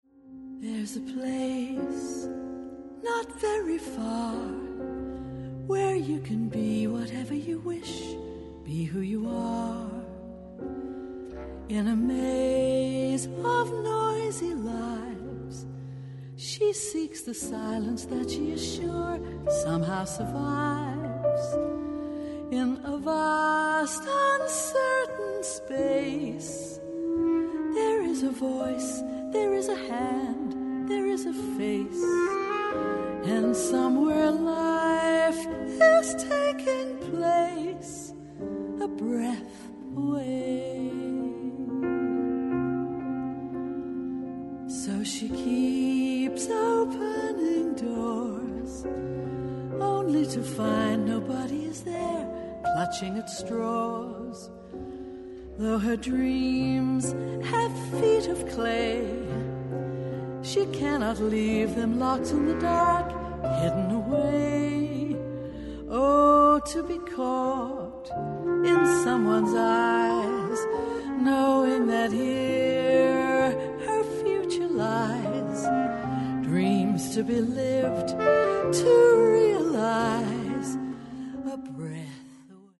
Recorded December 2012, Auditorio Radiotelevisione, Lugano
Piano
Sop. Sax, Bs Clt